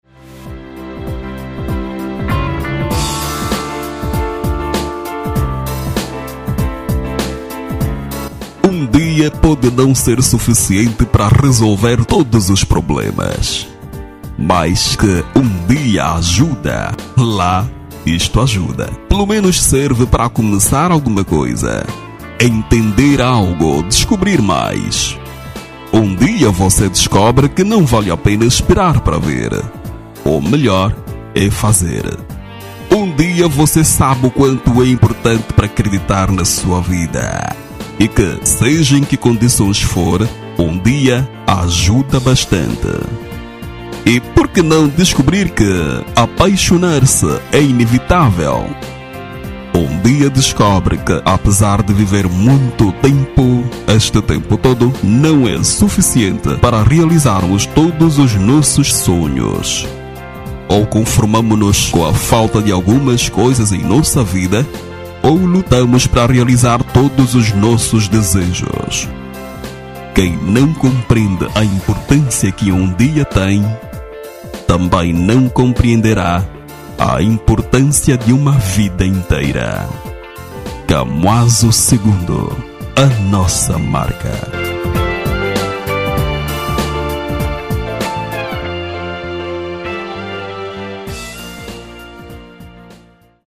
Masculino
Um Dia - Mensagem Reflexiva